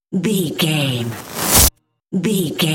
Trailer raiser
Sound Effects
Fast paced
In-crescendo
Atonal
bouncy
driving
futuristic
intense
dramatic
riser